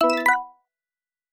Special & Powerup (44).wav